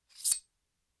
swordSheath1.ogg